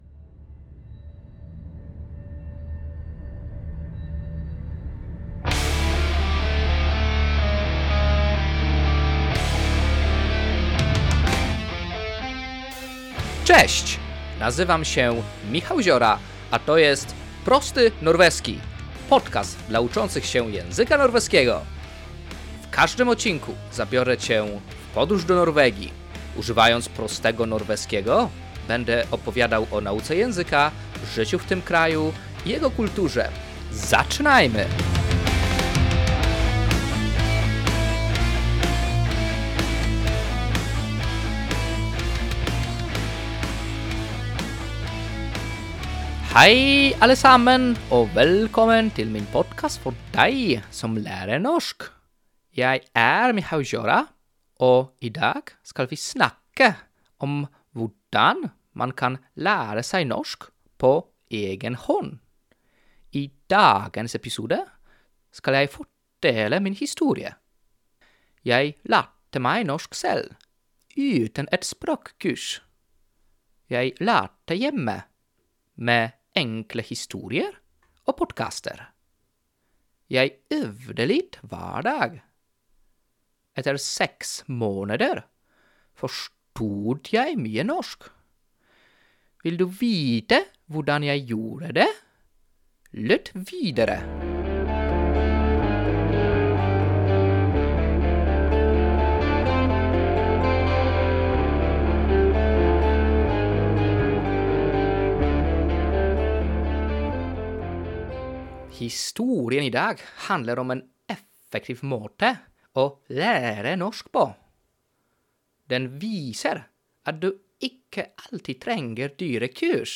Bardzo proste wprowadzenie (A2)
Muzyka w intro: